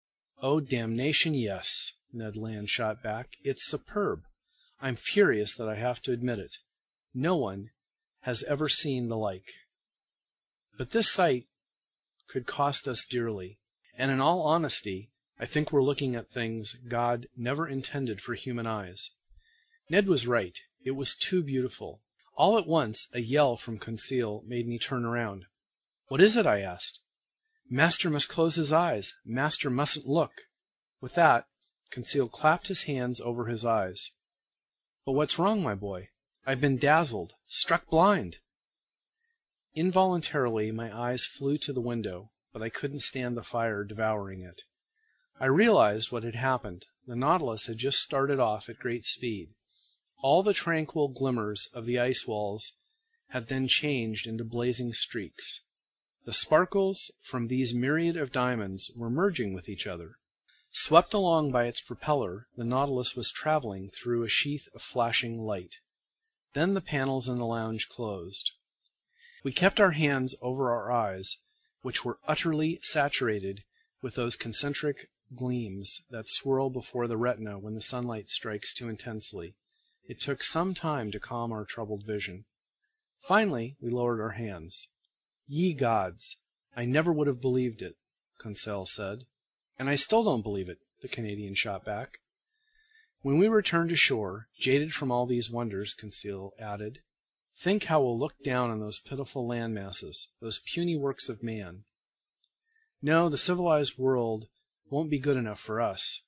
在线英语听力室英语听书《海底两万里》第466期 第28章 惊奇还是意外(8)的听力文件下载,《海底两万里》中英双语有声读物附MP3下载